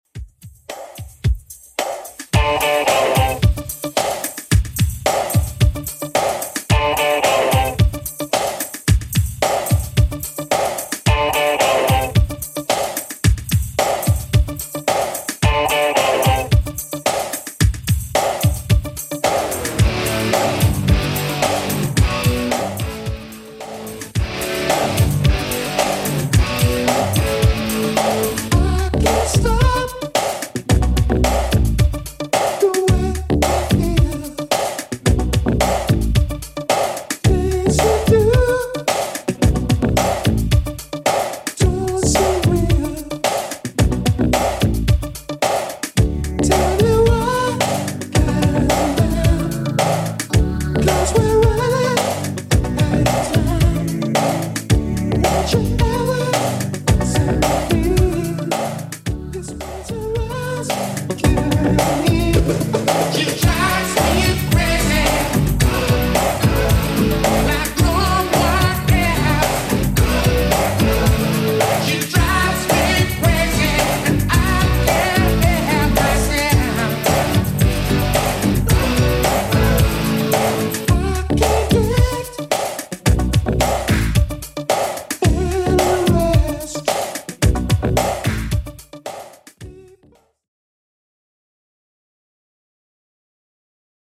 BPM: 110 Time